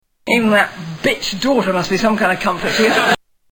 Category: Television   Right: Personal
Tags: TV Series Absolutely Fabulous Comedy Absolutely Fabulous clips British